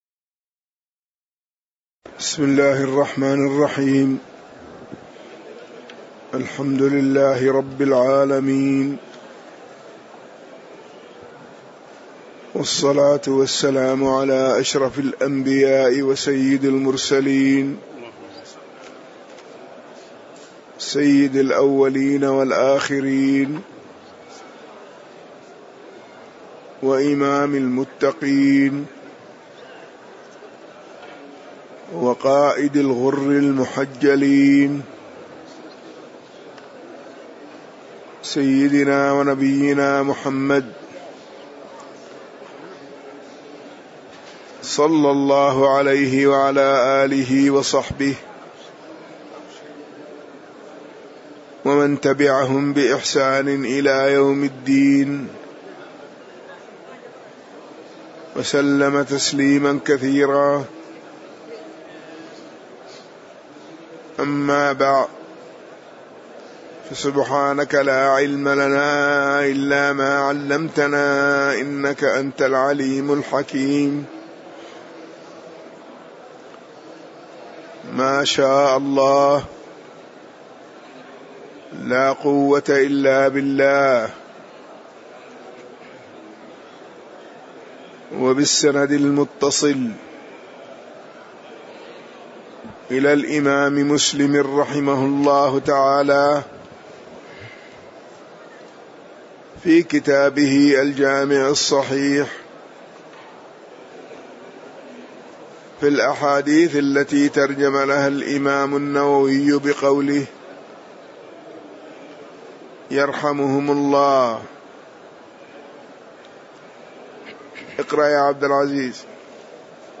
تاريخ النشر ١٣ ربيع الثاني ١٤٣٨ هـ المكان: المسجد النبوي الشيخ